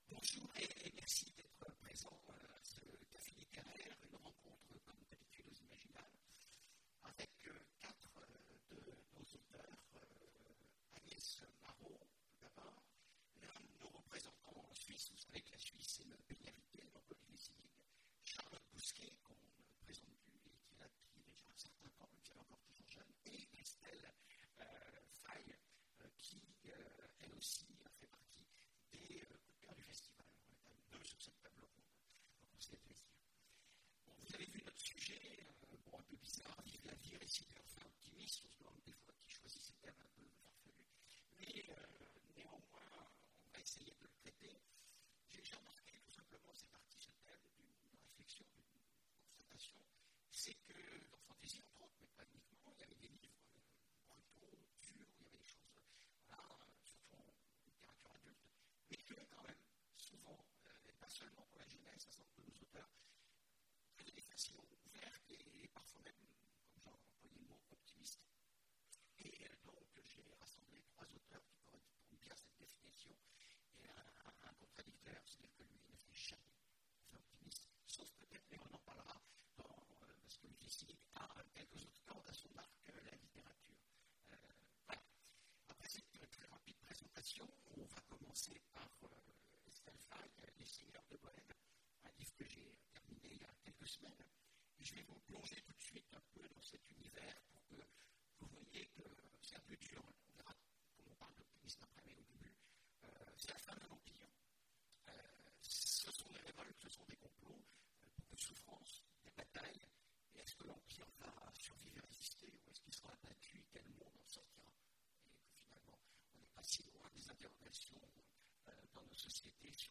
Mots-clés Conférence Partager cet article